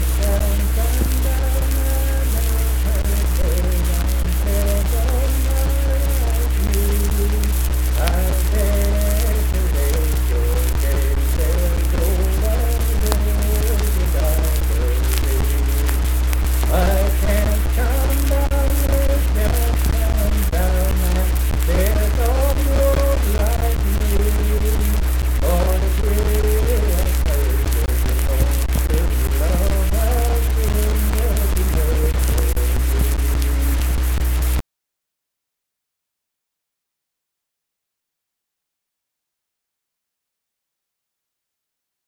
Unaccompanied vocal music
Verse-refrain 2(4).
Performed in Kanawha Head, Upshur County, WV.
Voice (sung)